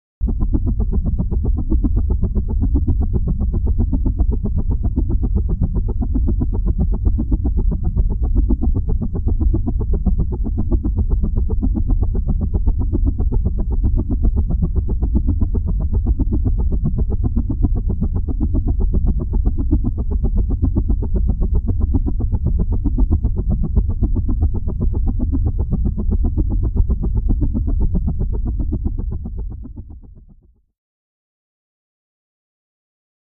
Aircraft-Helicopter | Sneak On The Lot
Helicopter ( Whisper Mode ); Modern Spinning Four-blade Helicopter Whooshes Without Engine Drone.